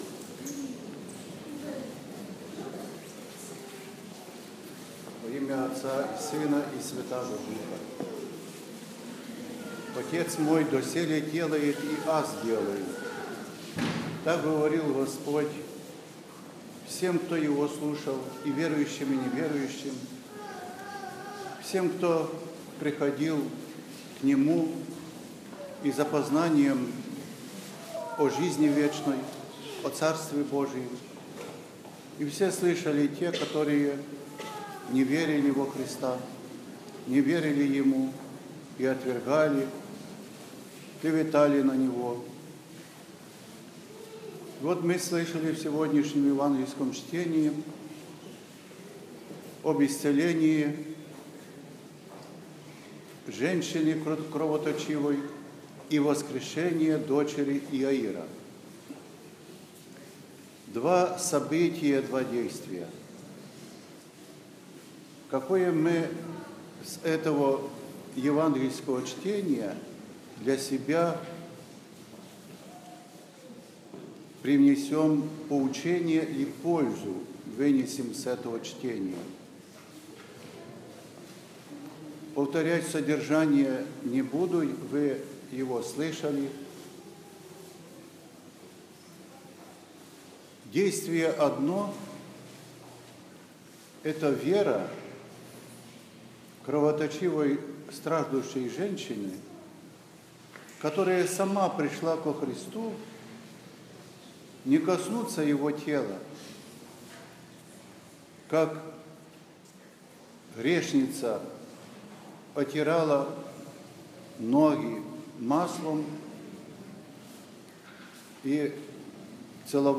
Воскресная проповедь | Храм Живоначальной Троицы у Салтыкова моста